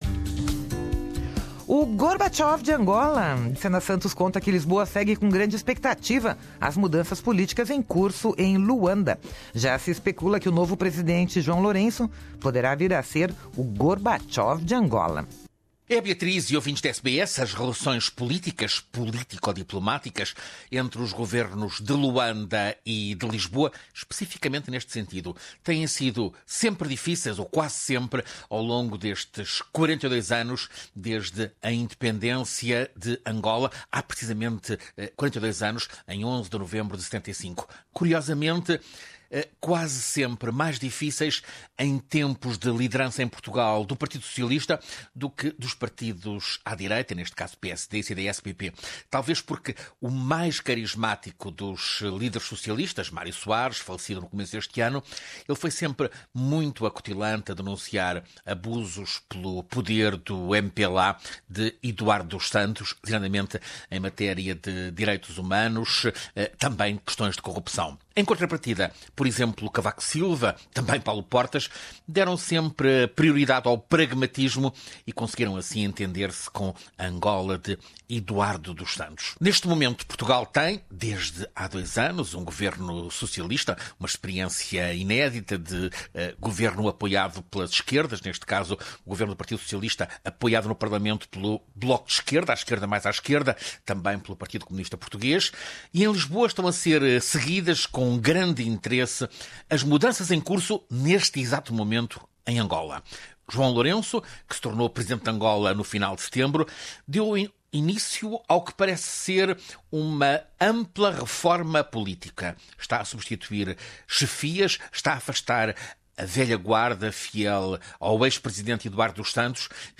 Ouça aqui a crónica, desde Lisboa.